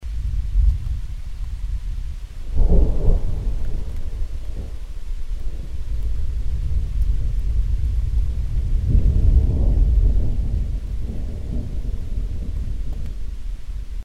Over the past few months I've started to dabble in nature audio recordings.
Thunder
thunder.mp3